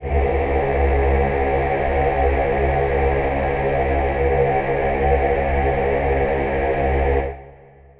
Choir Piano (Wav)
C#2.wav